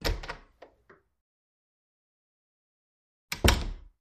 Creak
Door Open Close / Squeaks, Various; Wood Door Open Close 1